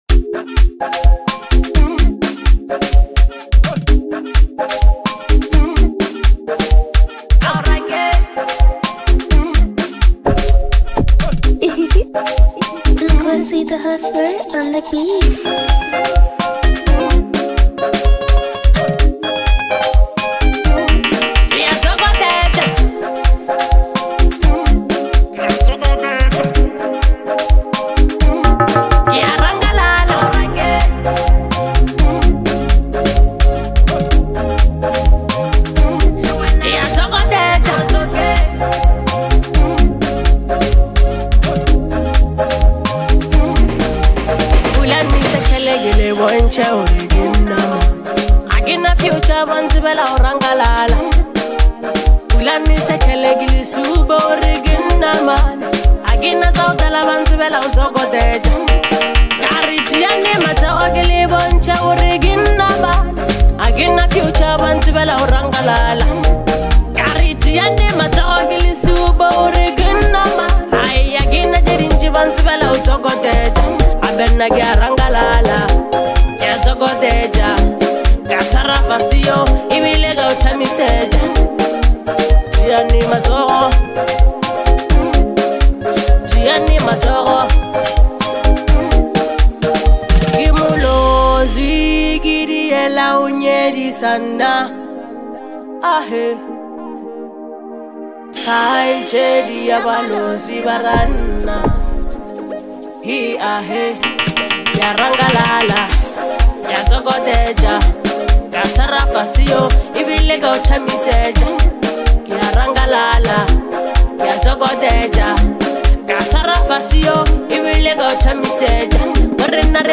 a powerful fusion of traditional sounds and modern beats